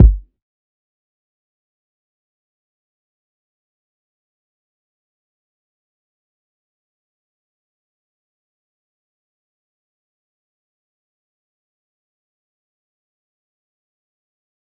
DMV3_Kick 6.wav